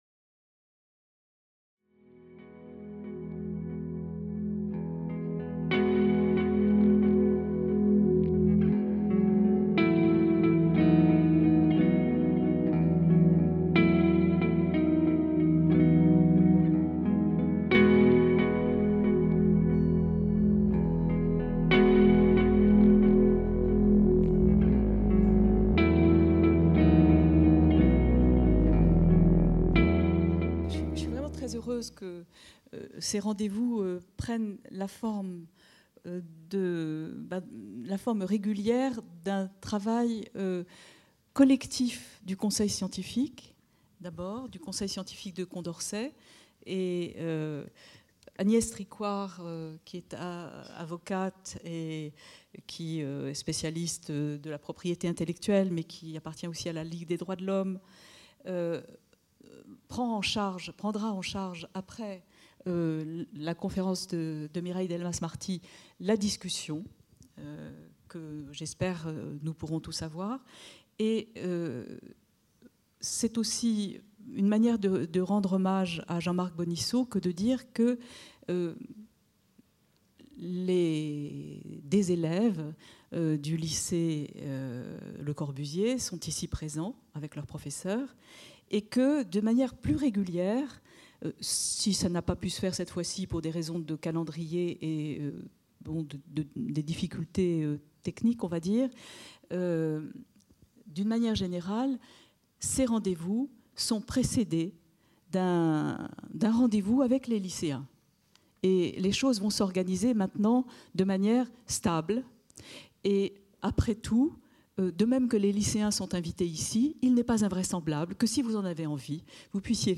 Ce Rendez-vous Condorcet est donné par Mireille Delmas-Marty, juriste au Collège de France.